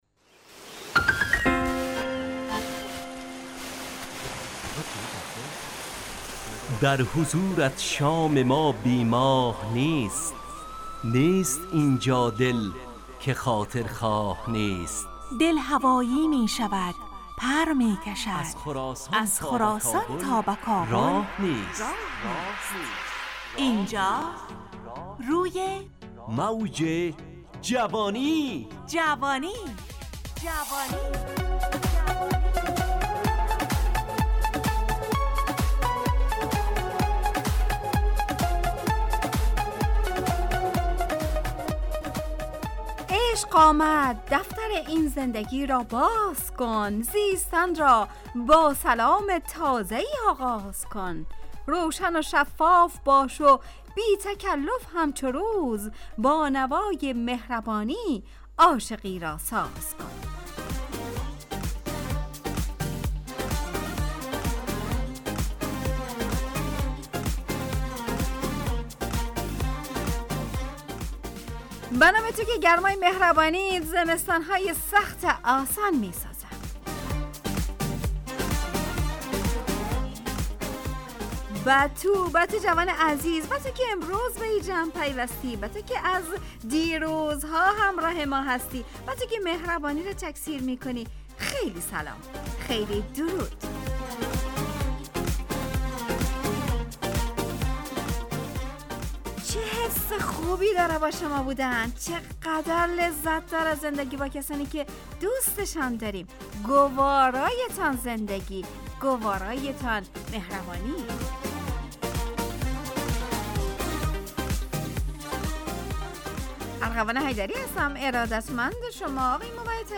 همراه با ترانه و موسیقی مدت برنامه 70 دقیقه . بحث محوری این هفته (خوب و بد)